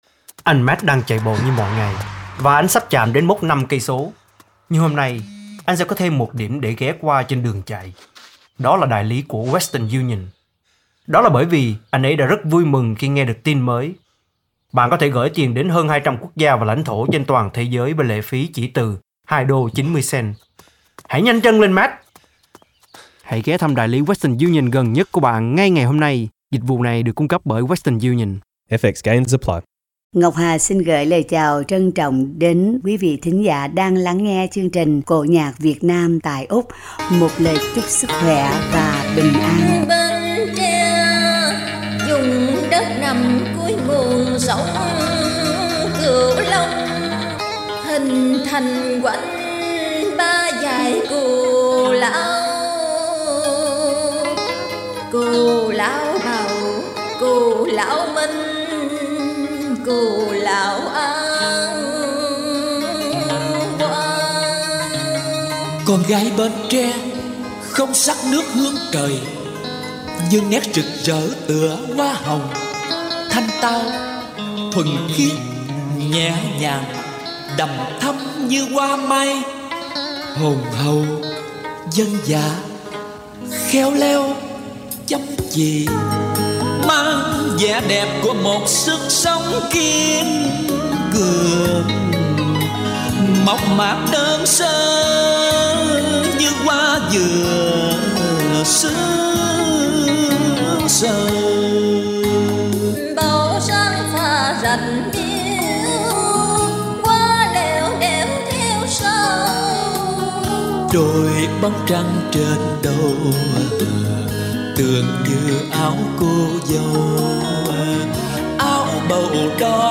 Với giọng ca ấm áp và truyền cảm
tân cổ